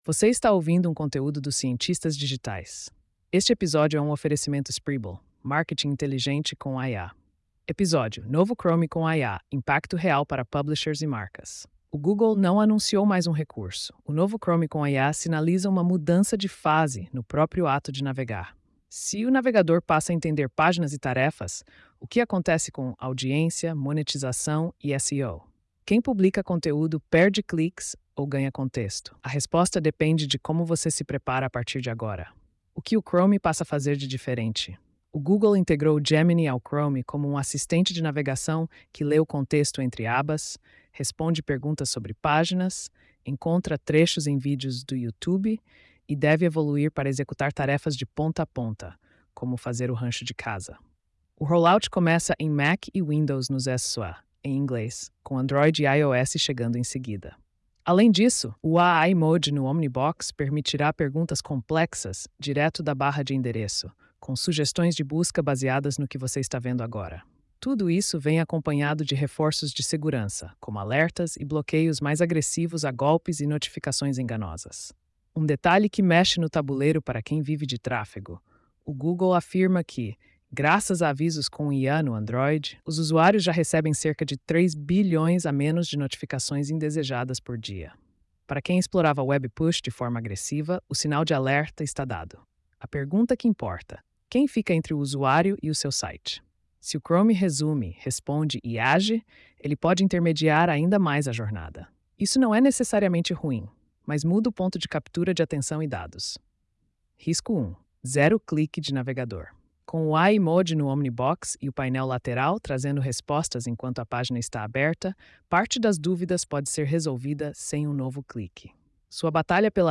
post-4413-tts.mp3